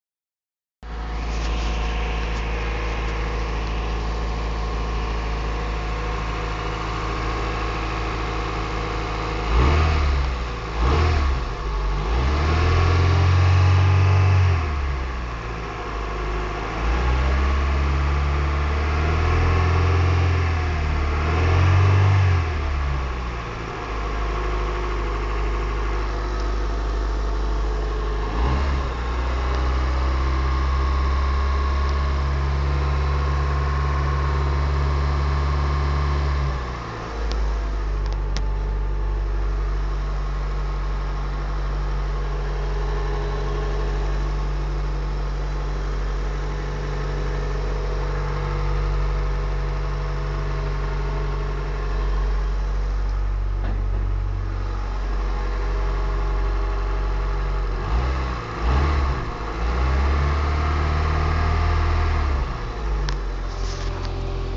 hört sich ganz normal an